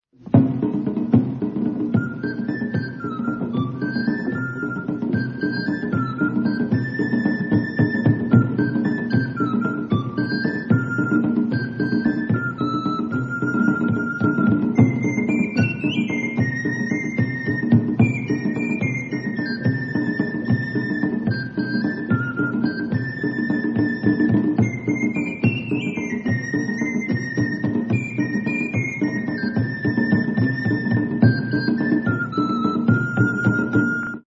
Garklein Flötlein.
nakers.
Recorded by BBC1 TV September 1979
Nakers are small drums, about the size of half a football, very like miniature timpani.
The instrument I am using in this recording is a 'Hopf' pearwood 'Renaissance' model, a long discontinued forerunner of the more authentic designs now produced by Kobliczek..